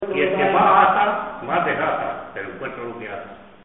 Zafarraya (Granada) Icono con lupa
Secciones - Biblioteca de Voces - Cultura oral